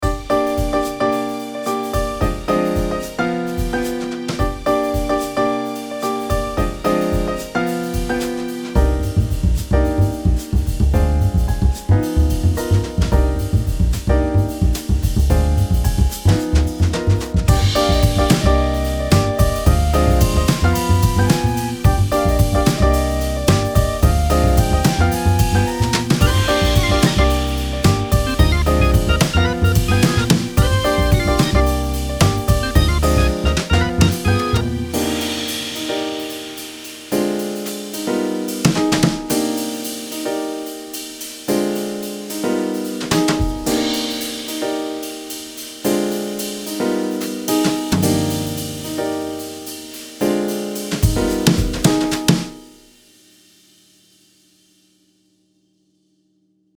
The Authentic Sound of Jazz Drumming
Experience the rich, soulful sound of authentic jazz drumming with Jazz Drum VST.
The VST offers a wide range of jazz techniques, including brush work, rimshots, rolls, and ghost notes, allowing you to tailor the sound to fit any style, from traditional to modern jazz fusion. With multiple velocity layers, it captures the natural depth and nuance of a real jazz drum kit.
Jazz-Drum-Demo.mp3